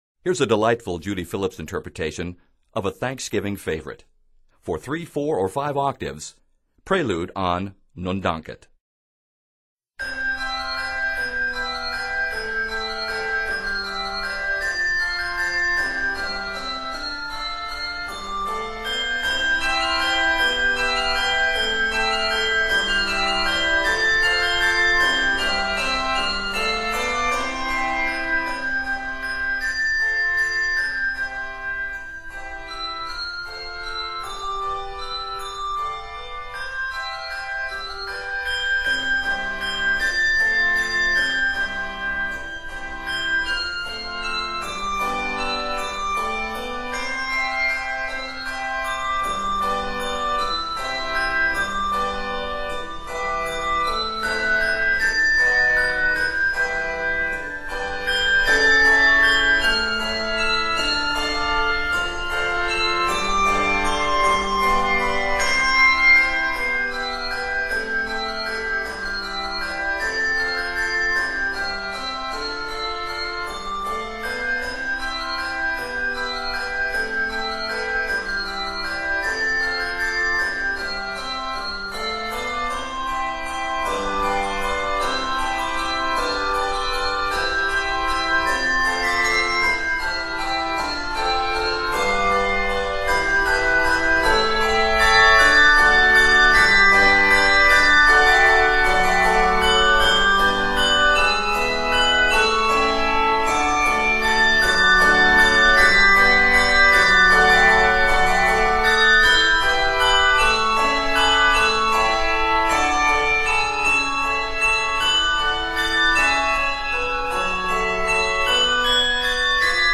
Flowing freely and cheerfully